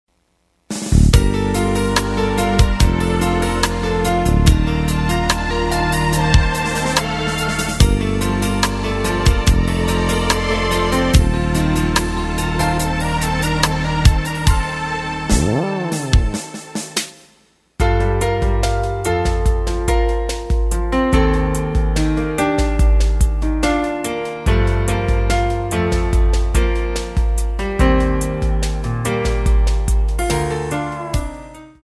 Gm
앞부분30초, 뒷부분30초씩 편집해서 올려 드리고 있습니다.
중간에 음이 끈어지고 다시 나오는 이유는